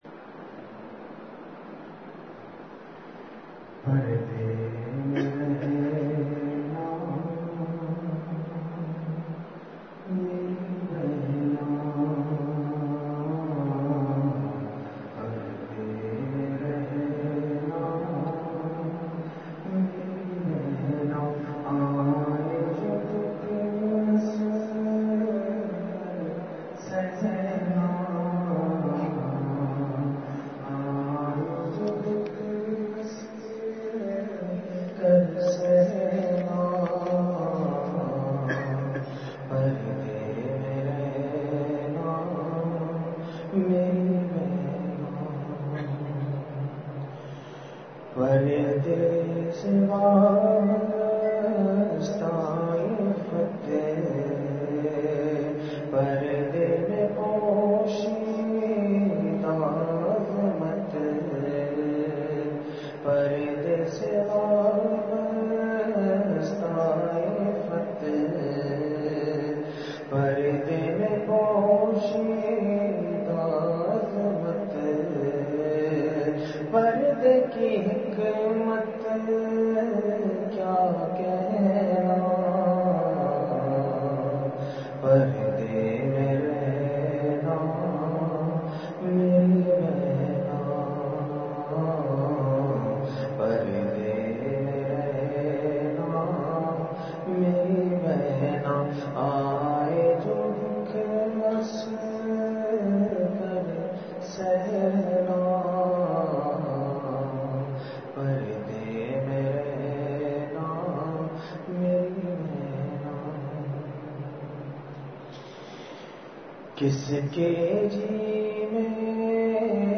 *بمقام:*مسجد البدر وارڈ نمبر 8کوٹ ادو
بعد مغرب بیان کا آغاز ہوا۔۔ بڑی تعداد میں شرعی پردہ سے خواتین نے بھی بیان سنا۔۔